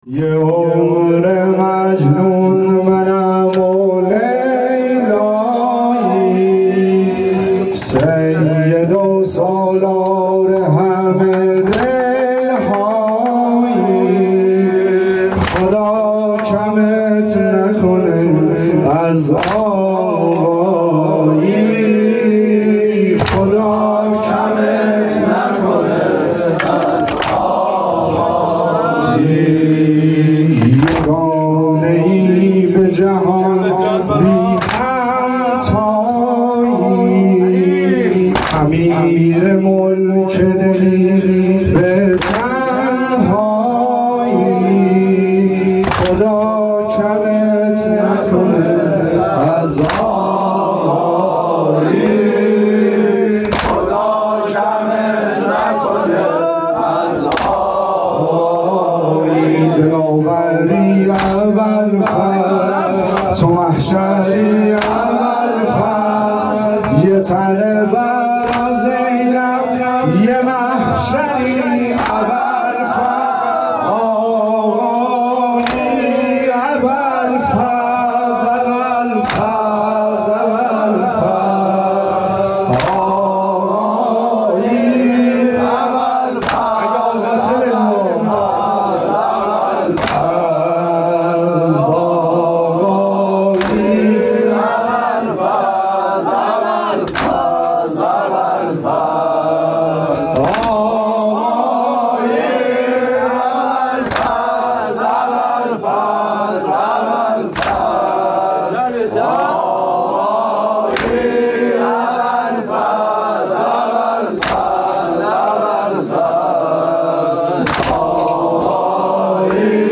حسینیه
نوای فاطمیه, مداحی فاطمیه